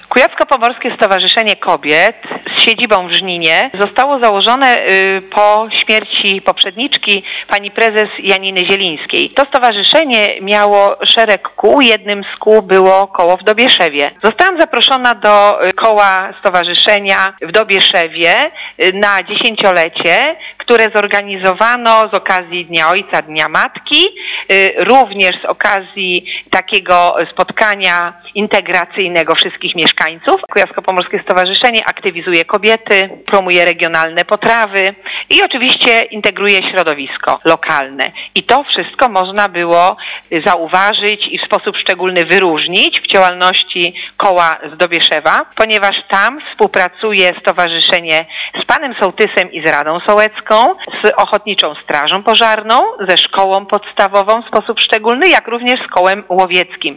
W ubiegłą sobotę w Dobieszewie (gmina Kcynia) odbył się Piknik Wiejski połączony ze świętowaniem 10-lecia istnienia Stowarzyszenia Kobiet Wiejskich w Dobieszewie, a także Dnia Matki i Ojca.